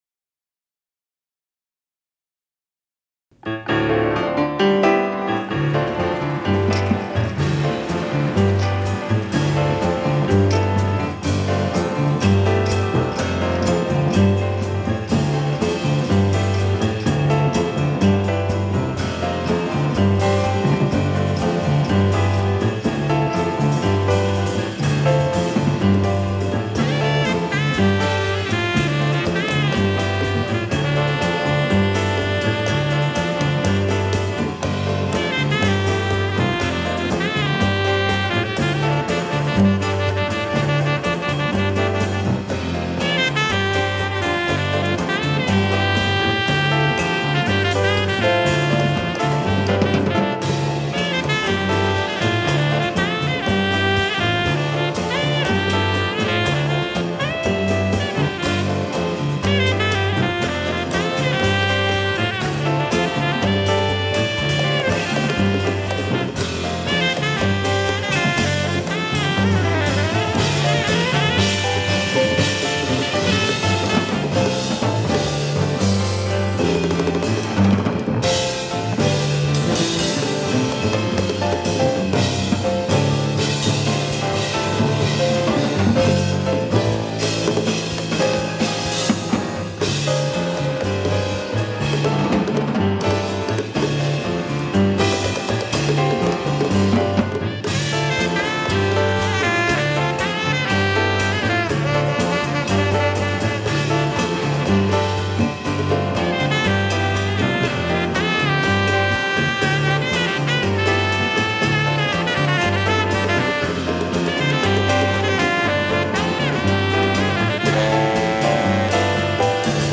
In concert
reeds
bass
drums
percussion.